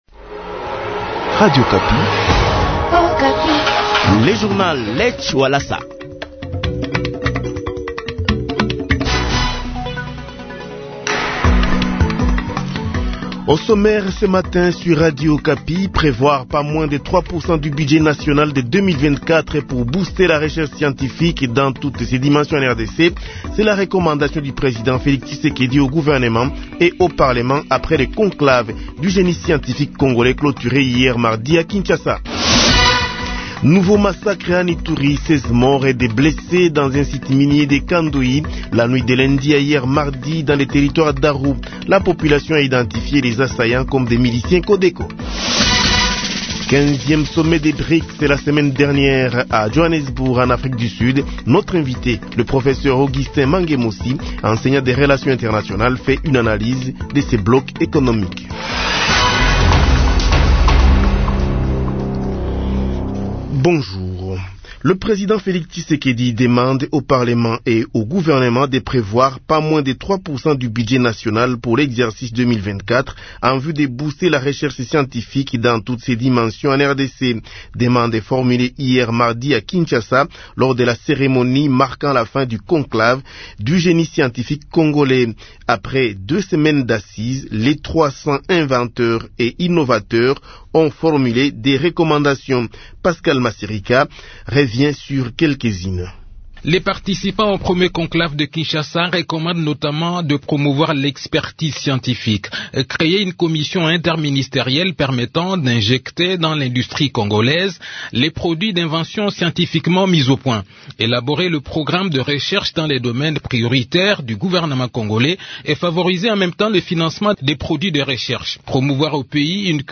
Journal Matin du Mercredi 30 Août 2023